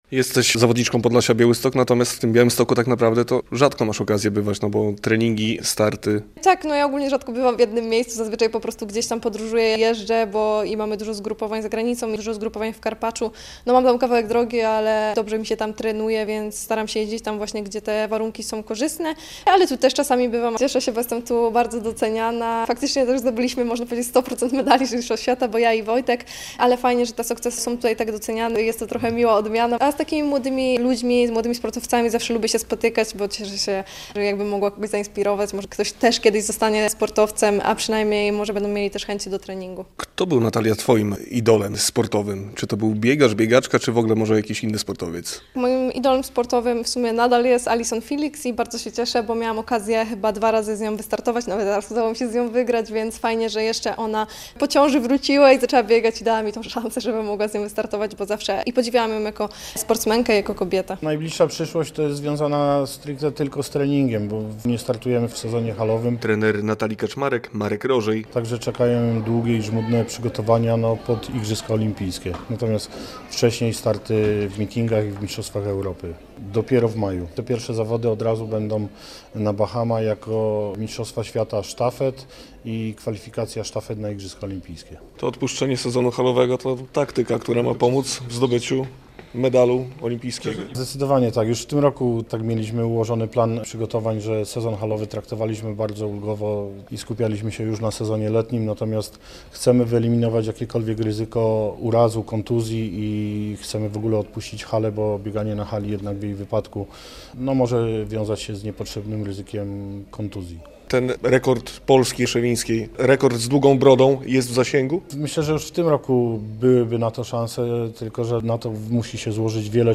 rozmawia
Spotkanie w Książnicy Podlaskiej poprowadził znany komentator sportowy Przemysław Babiarz.